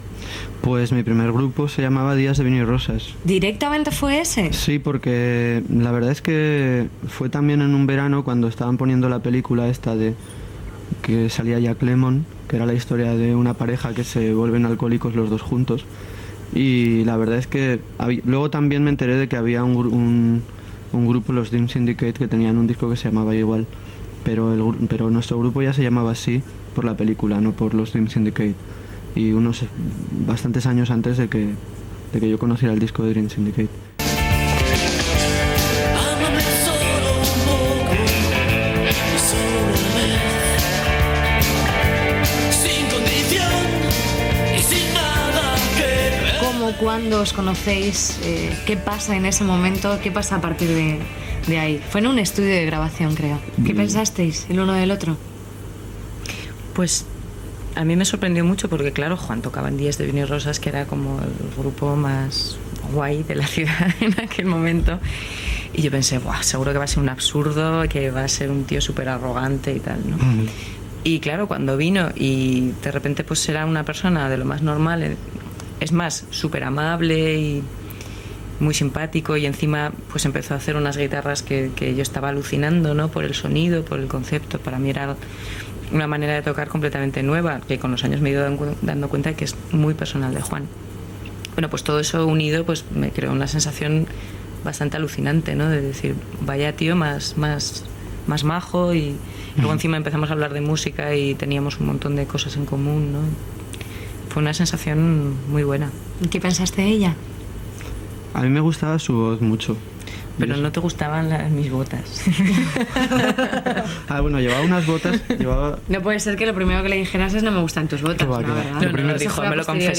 Entrevista al duet Amaral (Eva Amaral i Juan Aguirre)
Musical